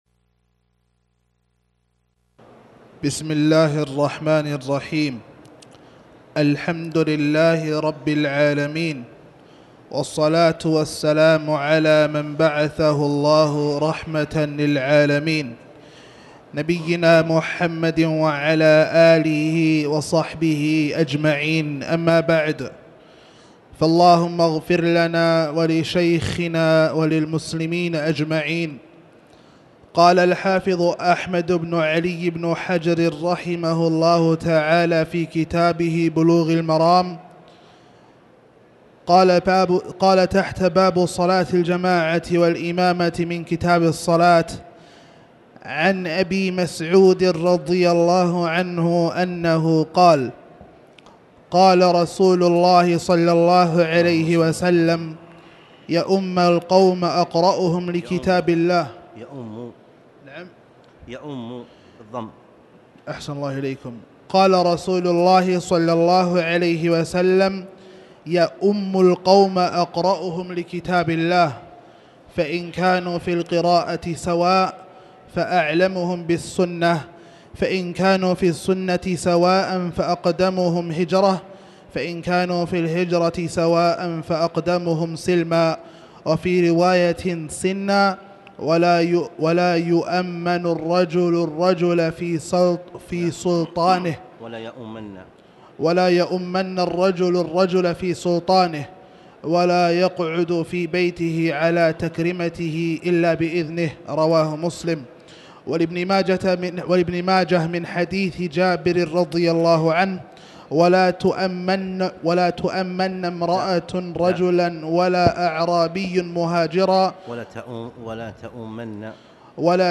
تاريخ النشر ٢٦ صفر ١٤٣٩ هـ المكان: المسجد الحرام الشيخ